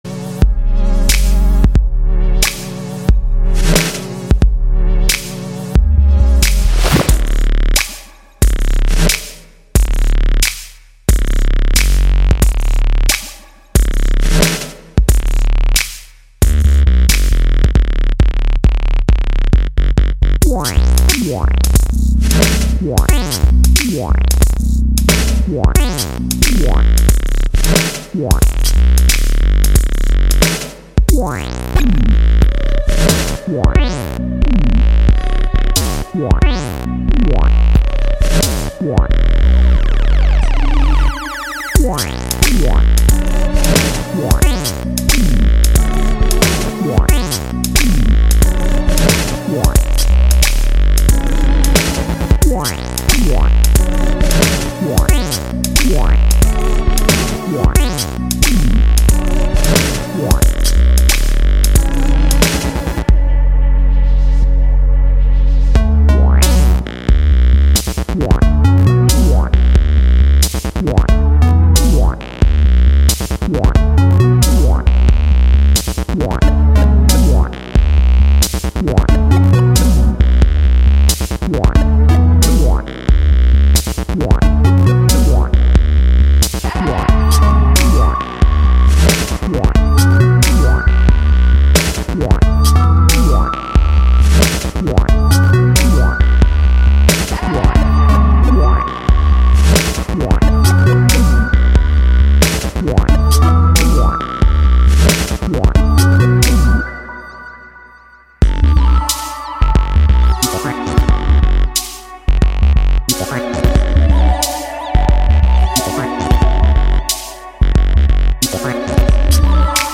Live from Pacesetters Kitchen Radio (Malaga, Spain)
Live from Pacesetters Kitchen Radio (Malaga, Spain): Basspistol Radio (Audio) Nov 27, 2025 shows Live from Pacesetters Kitchen Radio (Malaga, Spain) Basspistol Radio Station! 777% without commercials! RobotDJ-sets and live interventions!